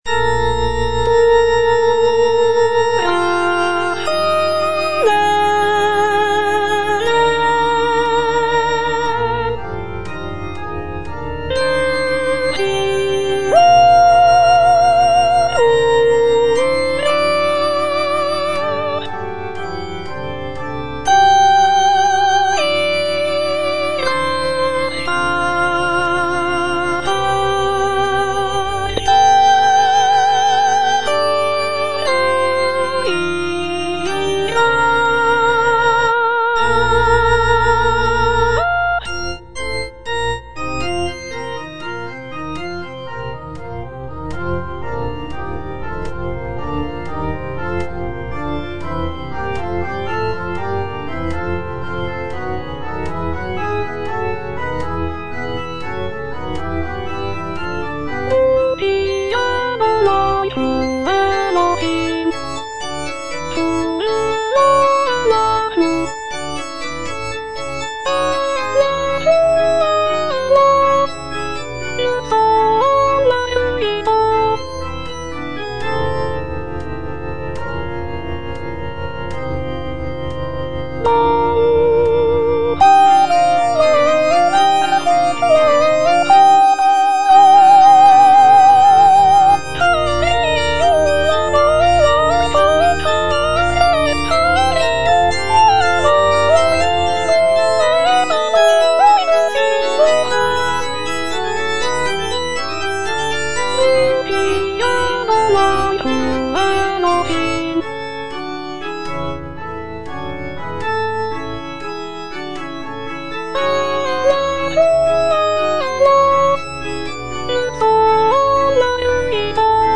soprano II) (Voice with metronome